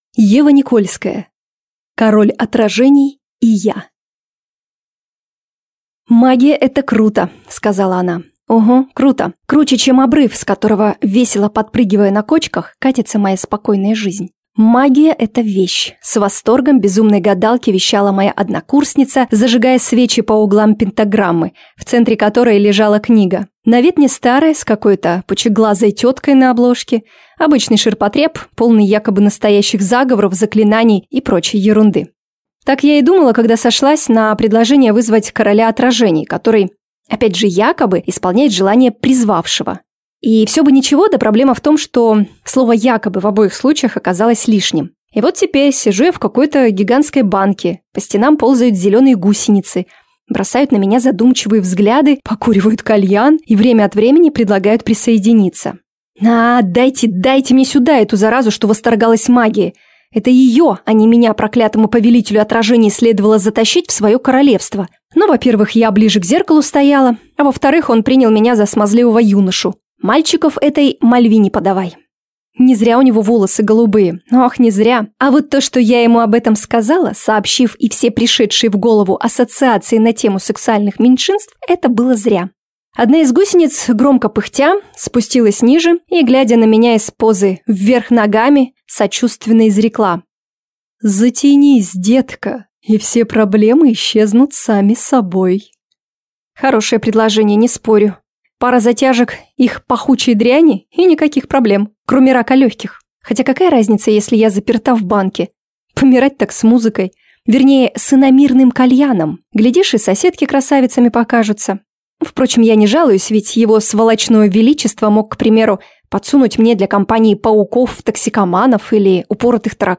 Аудиокнига Король отражений и я | Библиотека аудиокниг